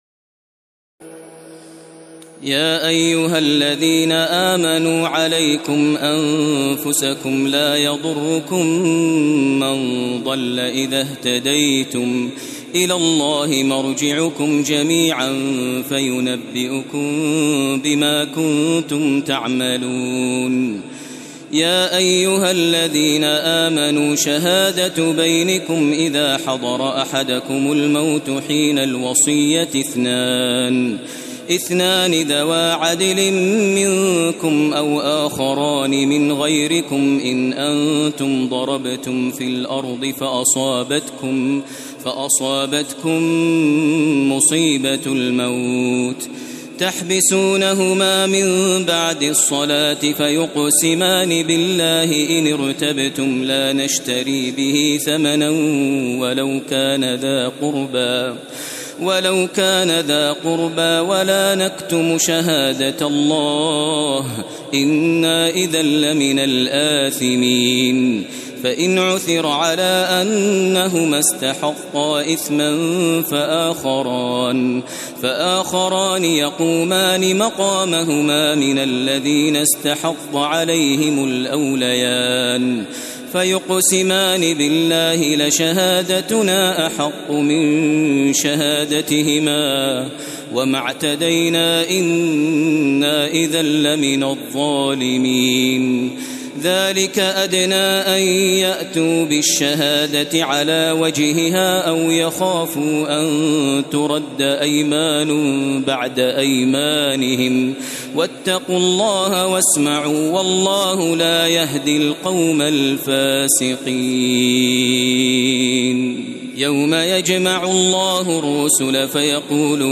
تراويح الليلة السادسة رمضان 1432هـ من سورتي المائدة (105-120) و الأنعام (1-73) Taraweeh 6 st night Ramadan 1432H from Surah AlMa'idah and Al-An’aam > تراويح الحرم المكي عام 1432 🕋 > التراويح - تلاوات الحرمين